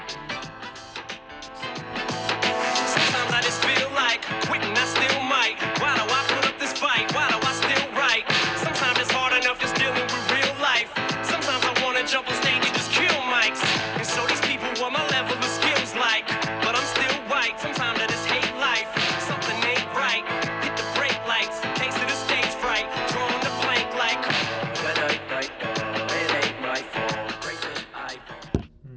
第二个是英文说唱。英文说唱的特点是语速快、歌词中单词连读情况多，识别结果中很多歌词中的单词连读、长难句识别准确，且没有受到背景音乐的干扰。